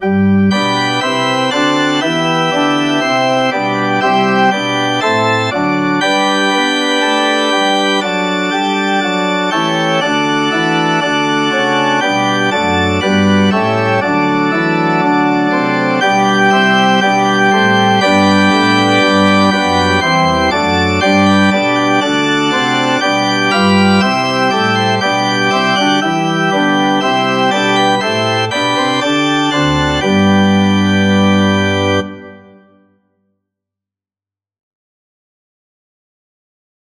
Organ version
Allegro (View more music marked Allegro)
4/4 (View more 4/4 Music)
Organ  (View more Intermediate Organ Music)
Classical (View more Classical Organ Music)